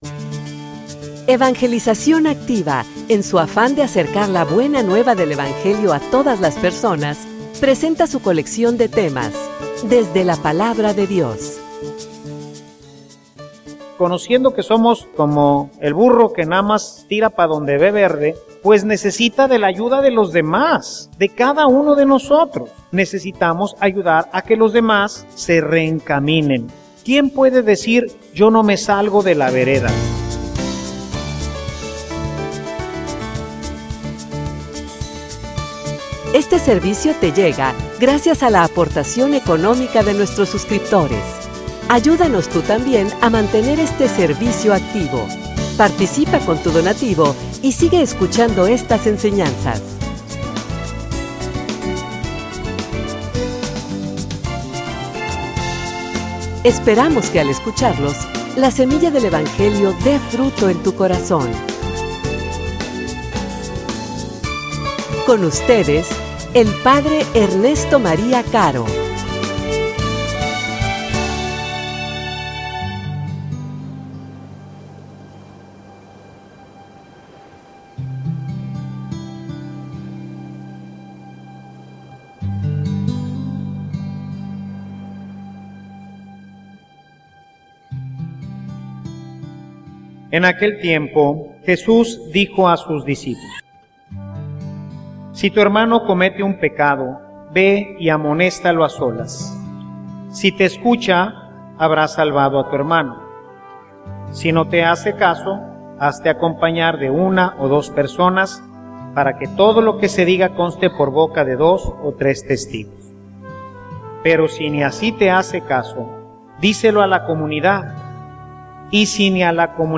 homilia_Muestra_el_amor_a_tu_hermano_corrigelo.mp3